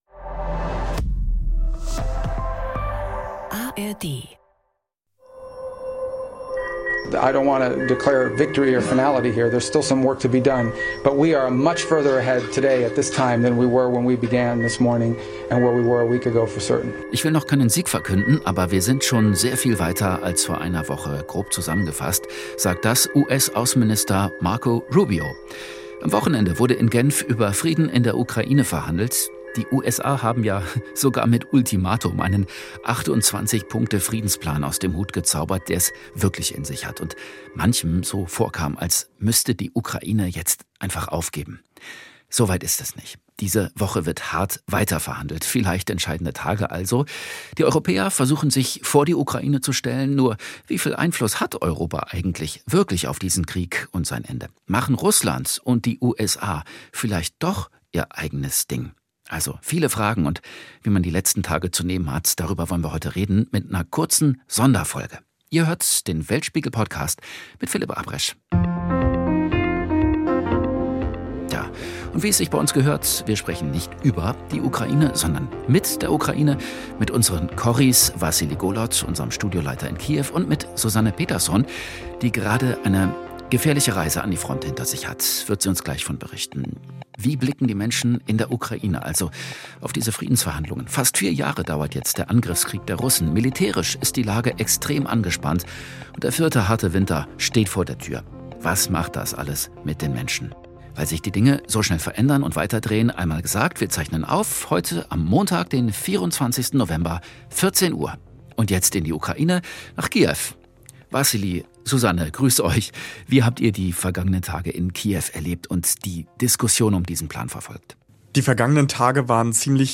Darüber sprechen wir in einem neuen Weltspiegel Podcast Extra.